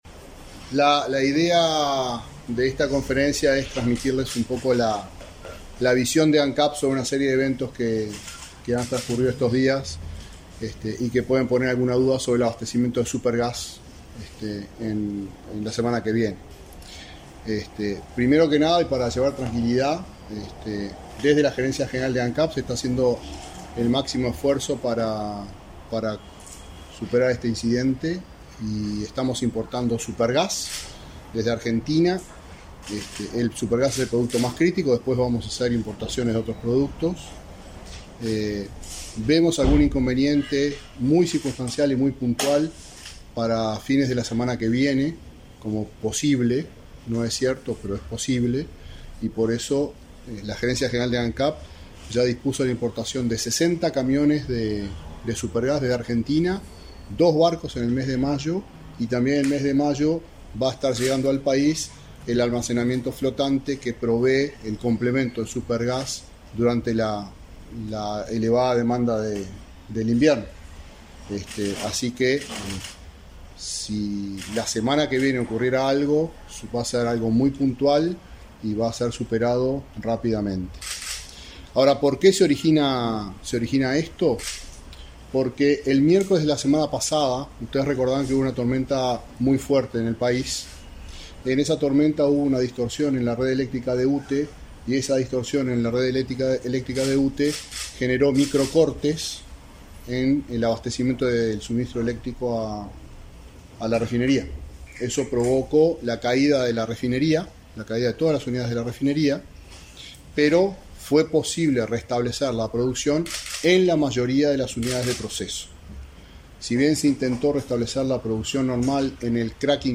Conferencia de prensa del presidente de Ancap, Alejandro Stipanicic